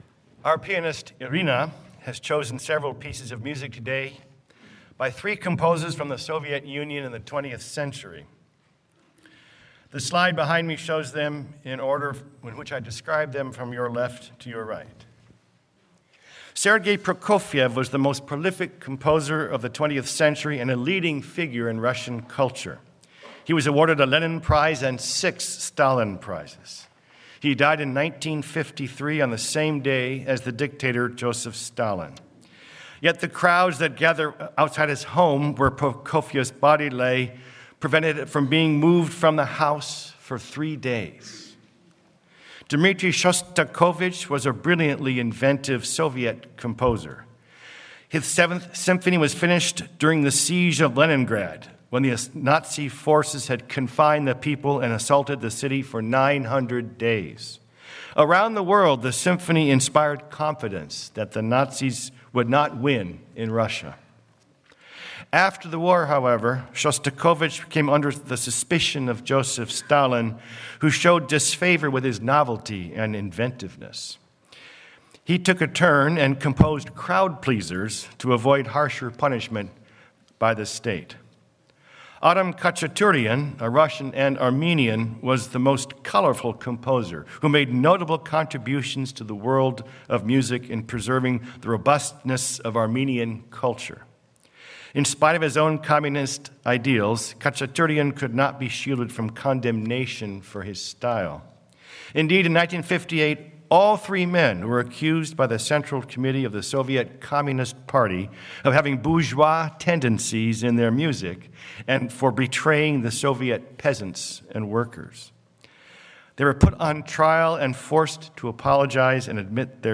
Sermon-When-Our-Enemies-Got-Free.mp3